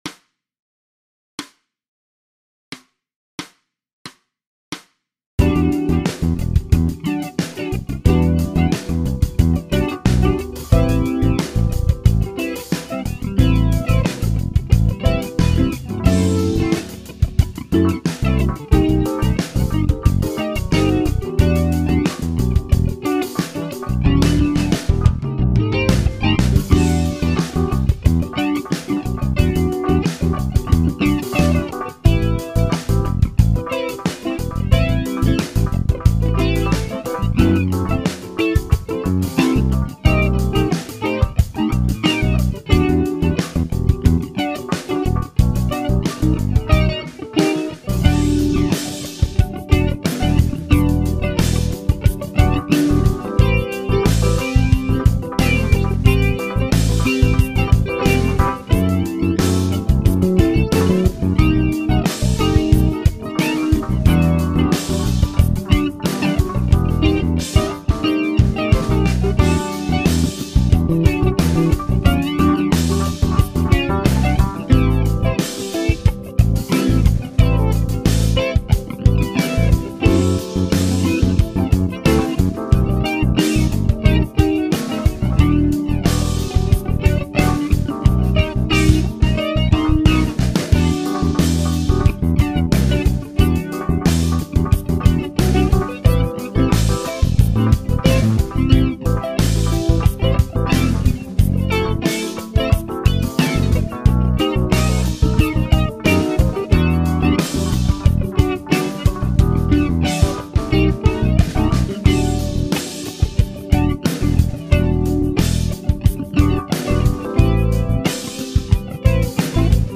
Base Funk per esercitarsi su assoli e riff
BASEFUNKGUITARRA.mp3